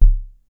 07_Kick_15_SP.wav